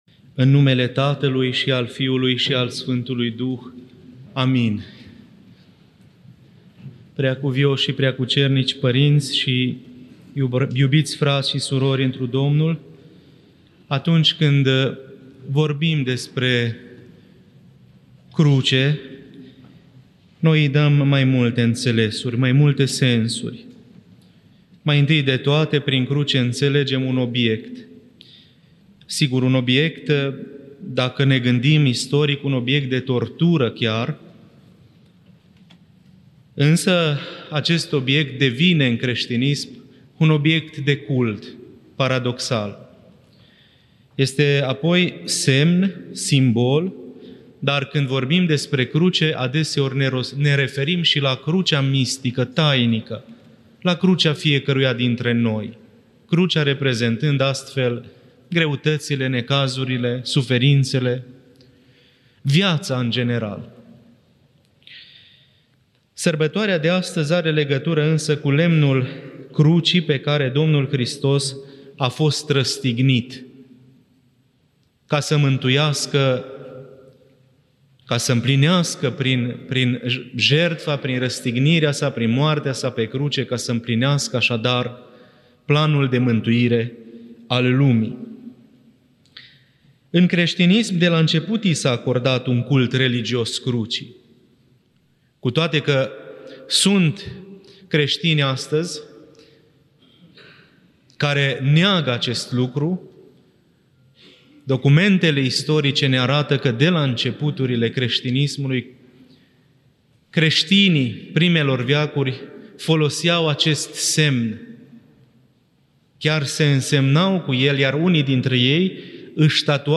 Predică la Înălțarea Sfintei Cruci
Cuvinte de învățătură